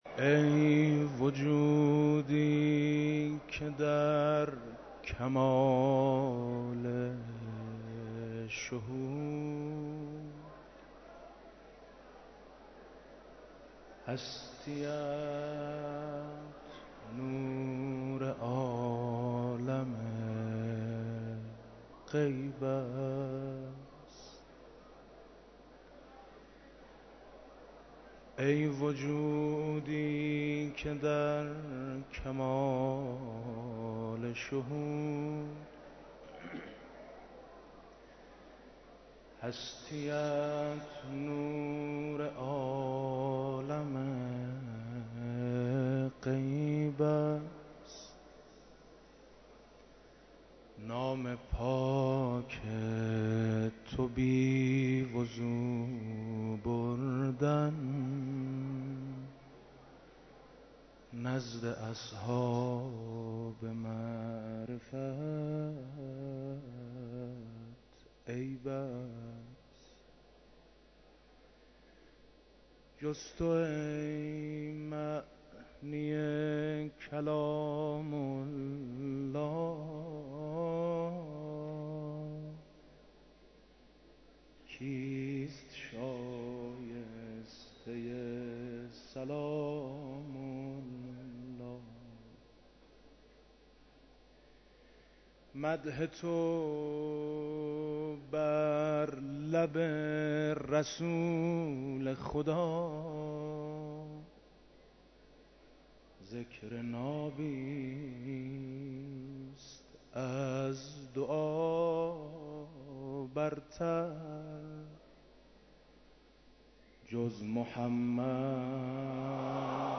مراسم عزاداری شام شهادت حضرت فاطمه زهرا سلام‌الله علیها
مداحی جناب آقای میثم مطیعی